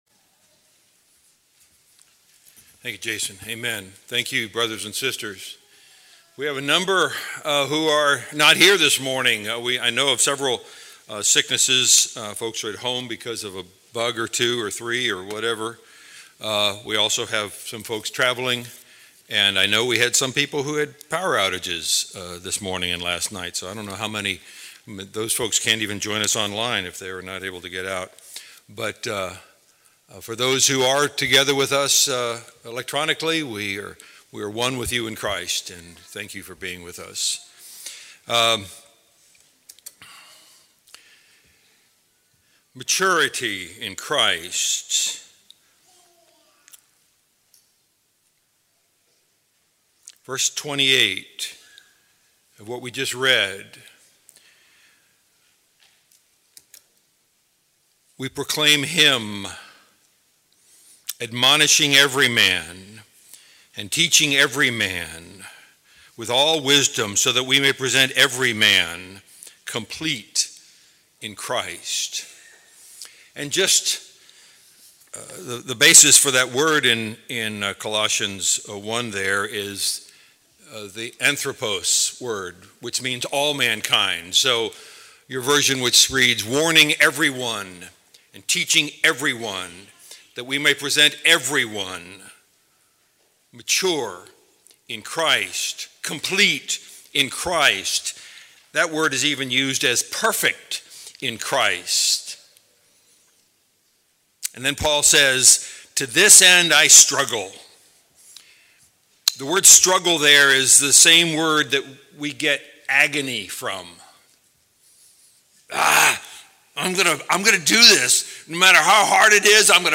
Valley church of Christ - Matanuska-Susitna Valley Alaska
Audio Sermons